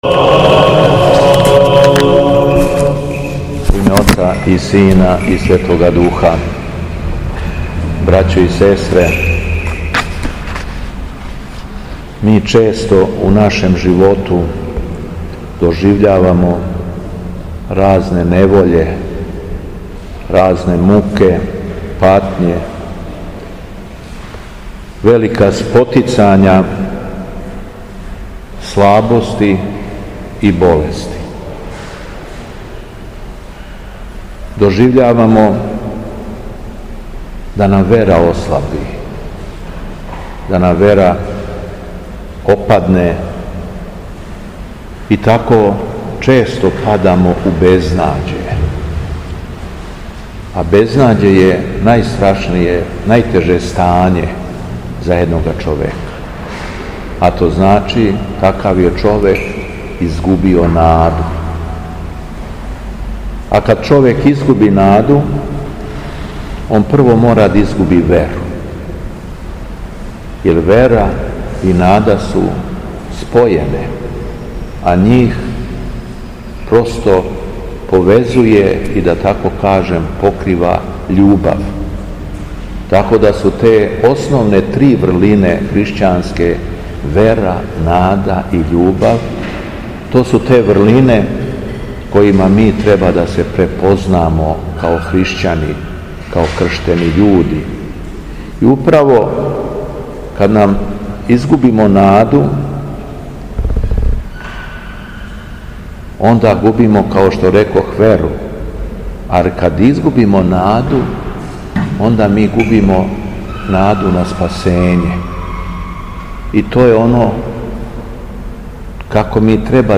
У понедељак седамнаести по Духовима, када наша Света Црква прославља свету мученицу Јефимију свехвалну, Његово Високопреосвештенство Митрополит шумадијски Господин Јован служио је свету архијерејску литургију у храму Светога Саве у крагујевачком насељу Аеродром.
Беседа Његовог Високопреосвештенства Митрополита шумадијског г. Јована
По прочитаном Јеванђељу по Луки, Његово Високопреосвештенство Митрополит Јован обратио се верном народу надахнутом беседом: